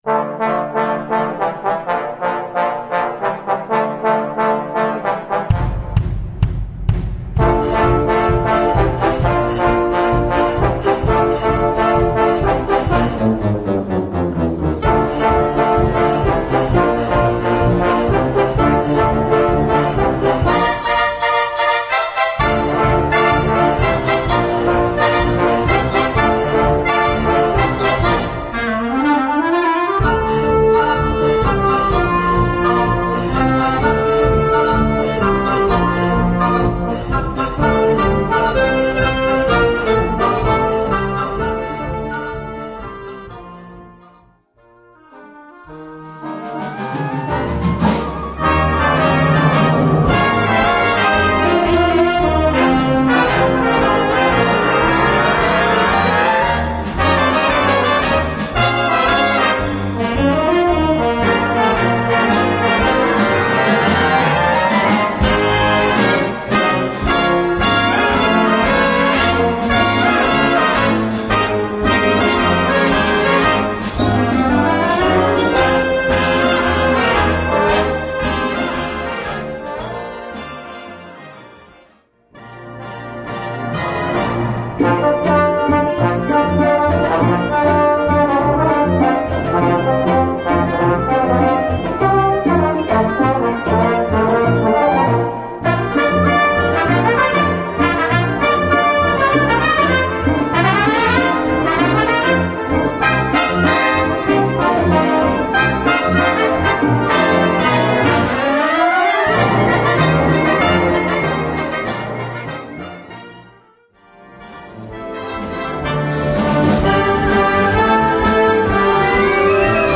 Gattung: Medley
Besetzung: Blasorchester